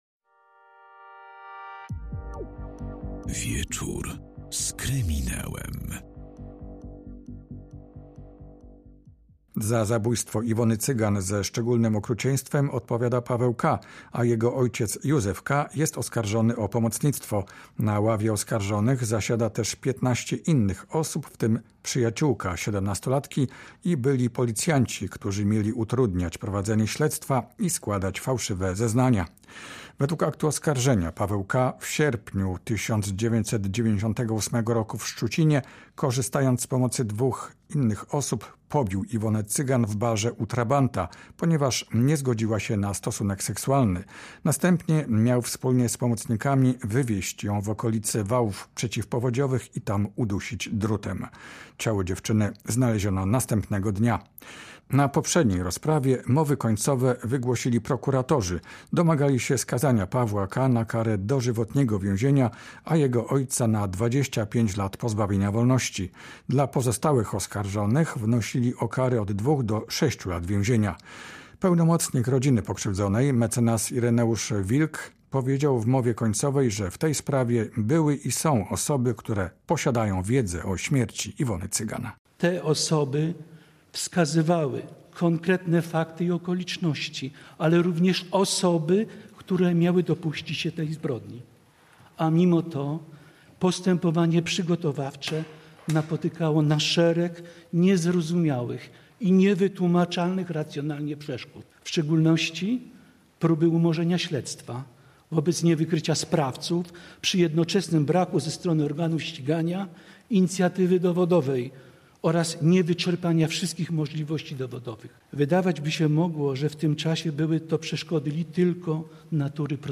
To jedna z najgłośniejszych spraw kryminalnych w Polsce. Przed sądem trwają mowy końcowe stron.